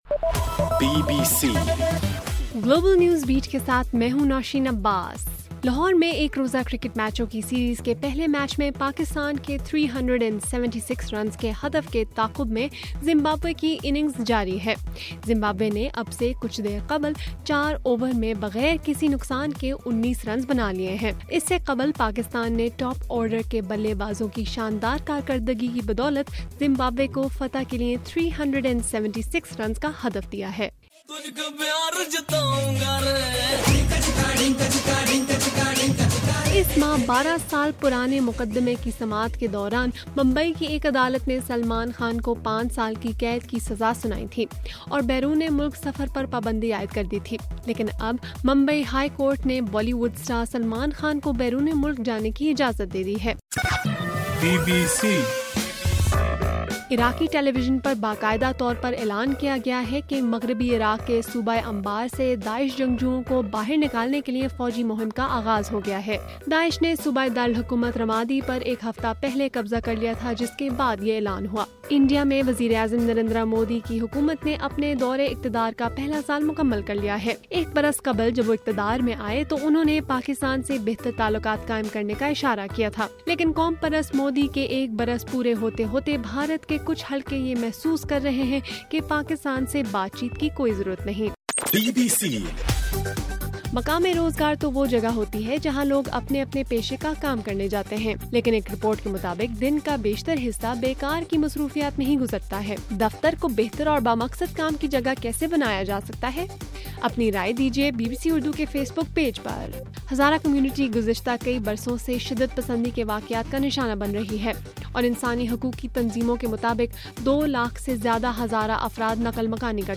مئی 26: رات 10 بجے کا گلوبل نیوز بیٹ بُلیٹن